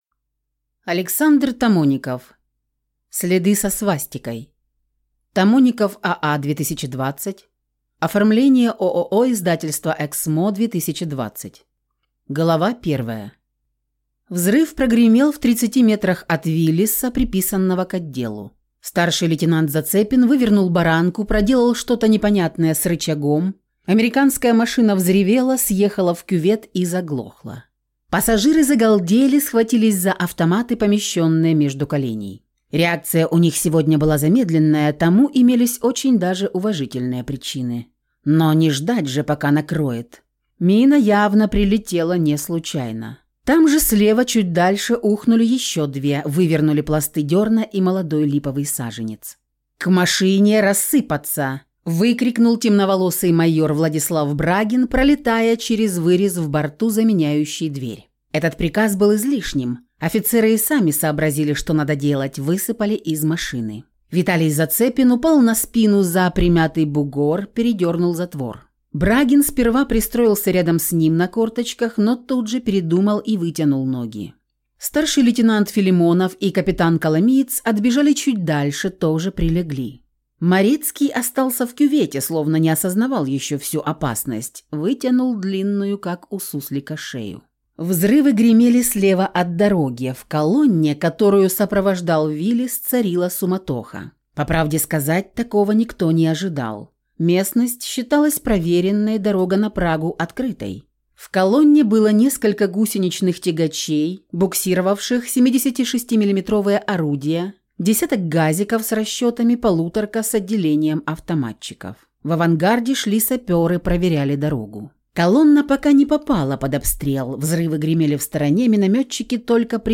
Прослушать фрагмент аудиокниги Следы со свастикой Александр Тамоников Произведений: 34 Скачать бесплатно книгу Скачать в MP3 Вы скачиваете фрагмент книги, предоставленный издательством